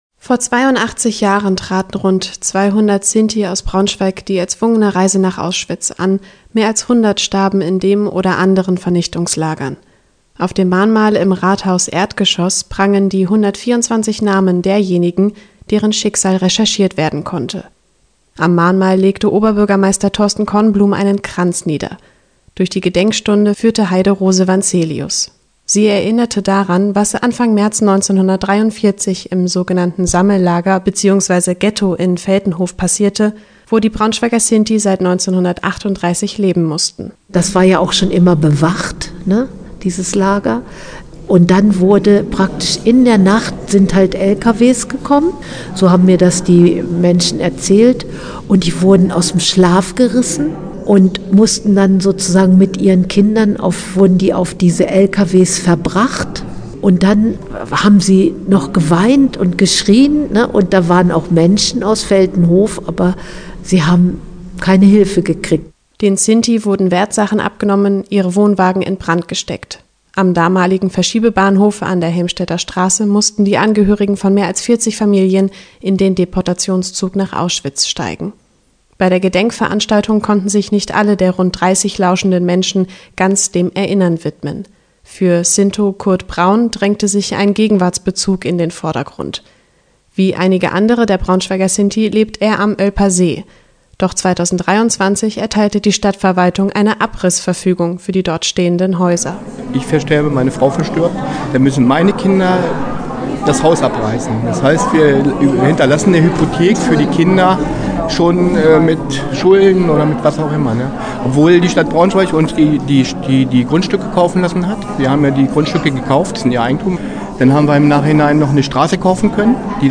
Erinnerung an die Deportation von Braunschweiger Sinti am 03.03.1943 ins Vernichtungslager nach Auschwitz: Eindringliche Gedenkfeier im Braunschweiger Rathaus